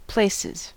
Ääntäminen
Ääntäminen US Tuntematon aksentti: IPA : /ˈpleɪ.sɪz/ Haettu sana löytyi näillä lähdekielillä: englanti Places on sanan place monikko.